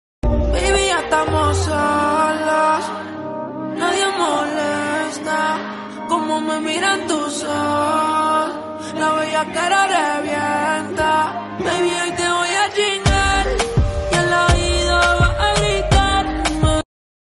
(ignoren la calidad y el efecto 💔)